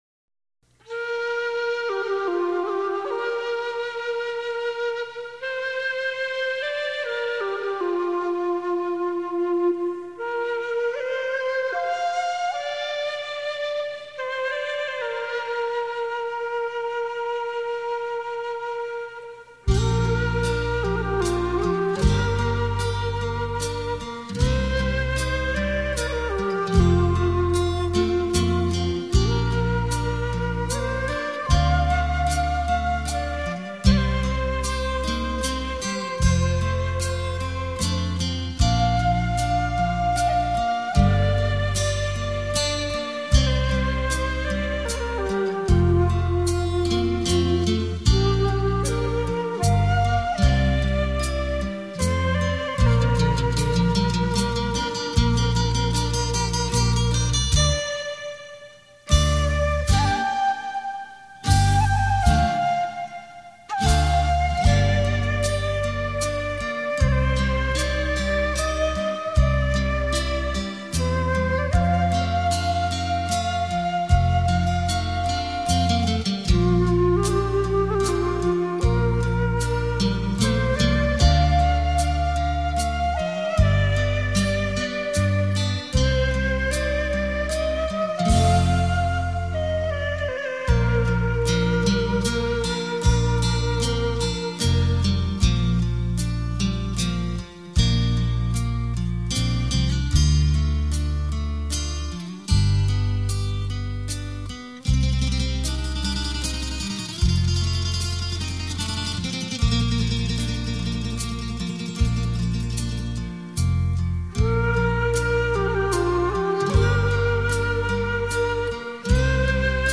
立体效果 环绕身历声 超魅力出击 全新风格精心制作